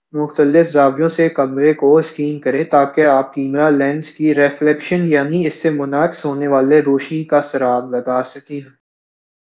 Spoofed_TTS/Speaker_13/116.wav · CSALT/deepfake_detection_dataset_urdu at main
deepfake_detection_dataset_urdu / Spoofed_TTS /Speaker_13 /116.wav